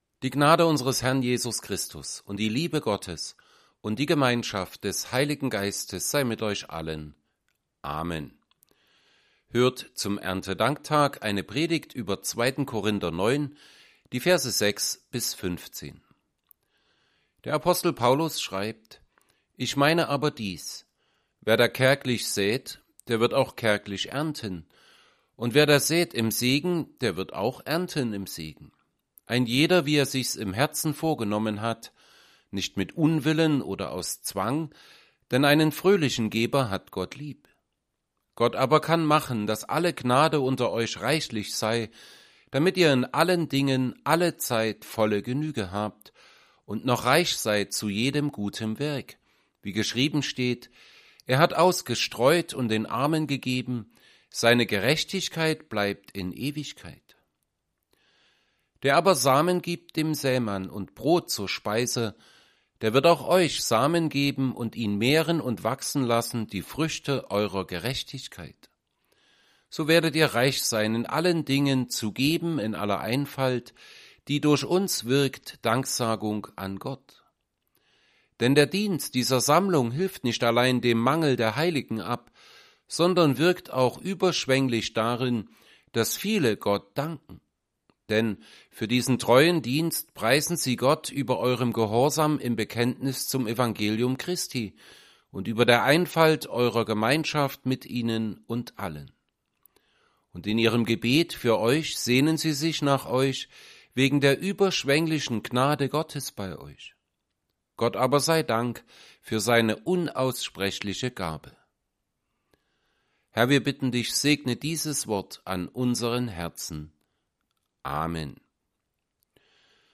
Predigt_zu_2Korinther_9_6b15.mp3